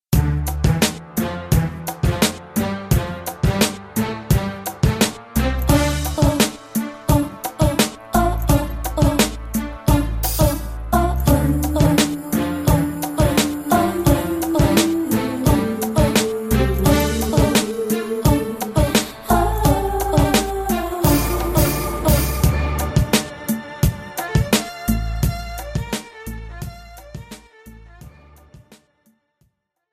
This is an instrumental backing track cover.
• Key – Dm
• With Backing Vocals
• No Fade